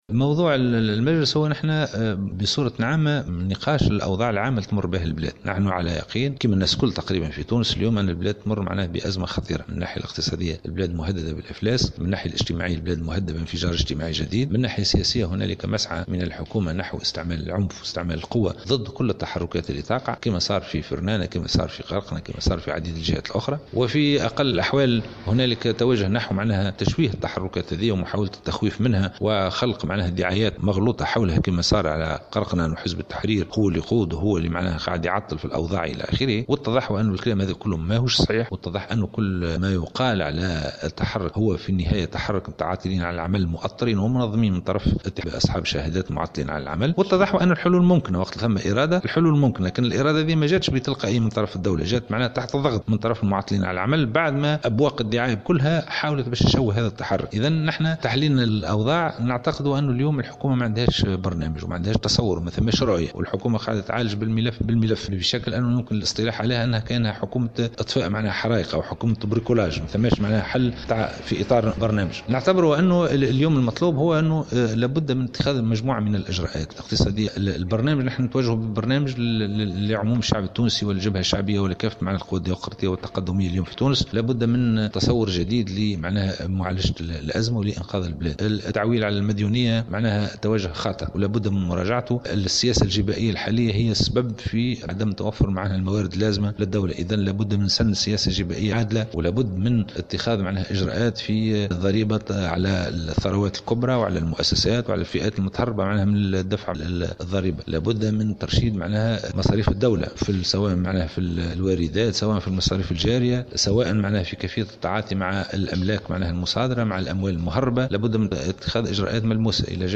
في تصريح